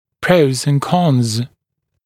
[prəuz ənd kɔnz][проуз энд конз]за и против